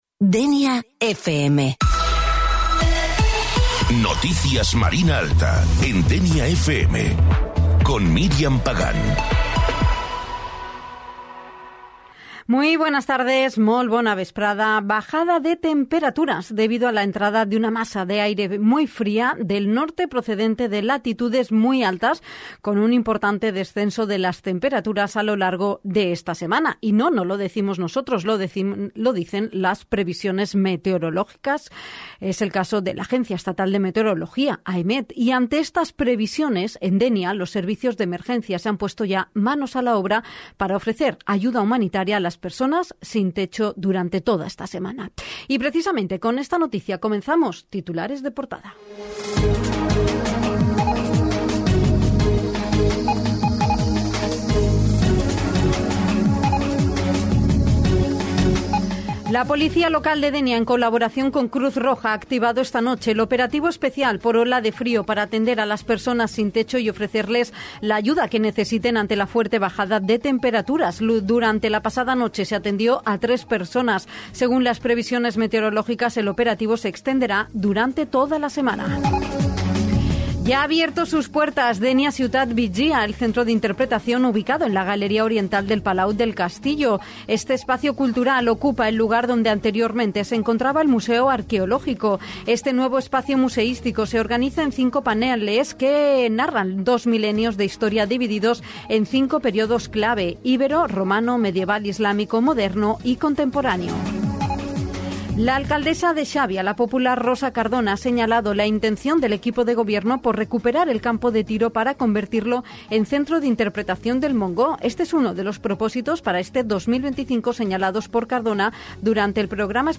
Pots escoltar en directe l’Informatiu de Dénia FM de dilluns a divendres a les 13.30 hores.
Sintonitza les notícies del dia amb Dénia FM